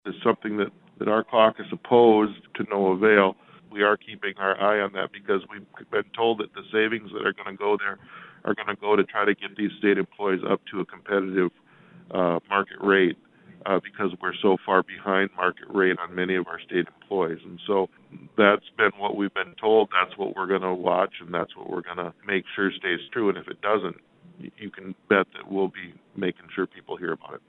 South Dakota House leadership recap 2021 Legislative Session during a March 11, 2021, news conference.